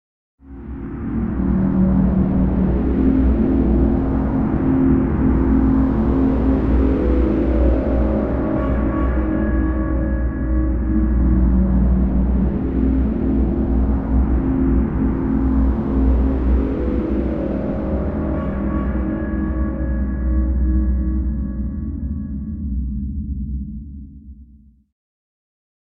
Scary Drone Ship Flying Sound Effect
A deep and powerful sci-fi ship sound that creates intense tension. This cinematic drone adds a dark and dramatic atmosphere to your videos, games, or trailers.
Scary-drone-ship-flying-sound-effect.mp3